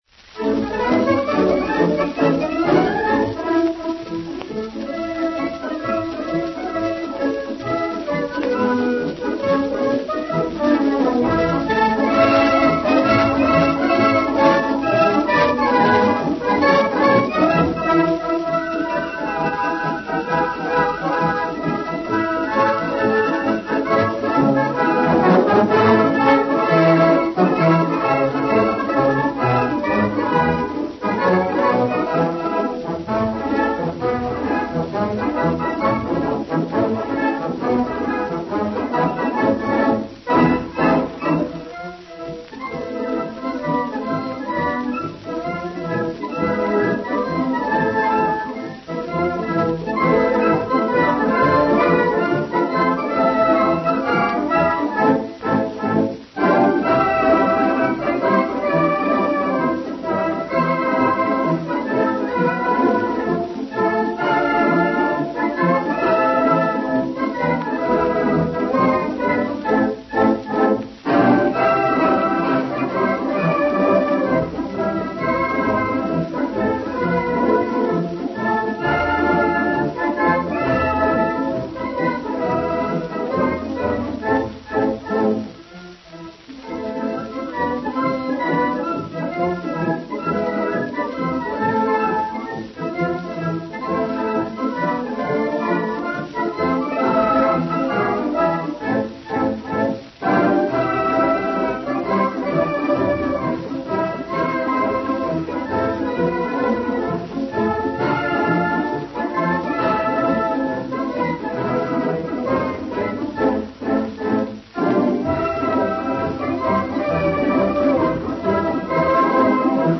Оркестр под картинкой — видимо, это сводный оркестр варшавской полиции — исполняет «Марш Пилсудского» (запись 1929—1930 годов). Если вас не слишком впечатлит самое начало марша, то потерпите чуть-чуть, ибо секунд через сорок там зазвучит мелодия знаменитой «Первой бригады» — мелодия, которую в Польше знает каждый:
Оркестр исполняет «Марш Пилсудского» (пластинка «White Eagle», № C-711-A)